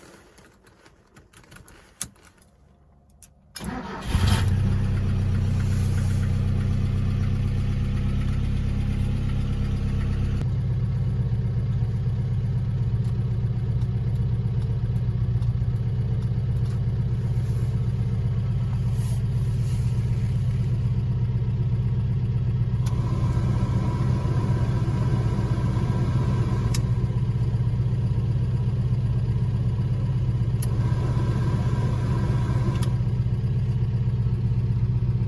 2. Запуск двигателя ГАЗ-24 Волга
gaz24-zapusk.mp3